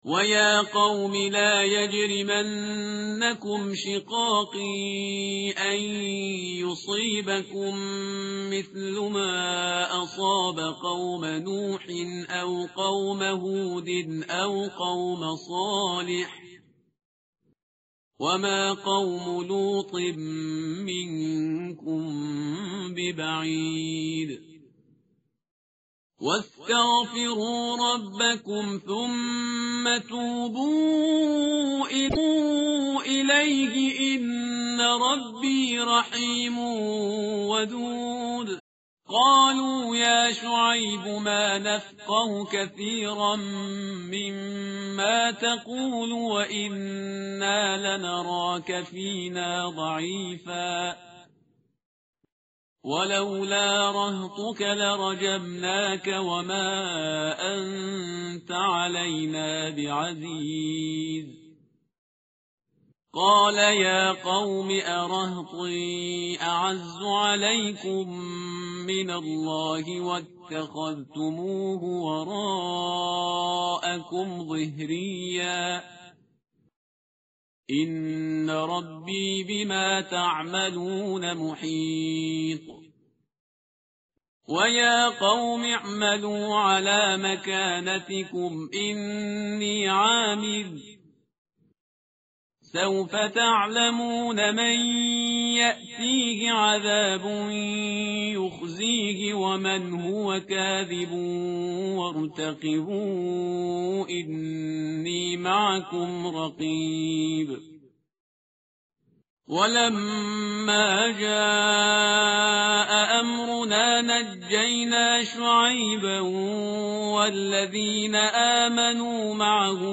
tartil_parhizgar_page_232.mp3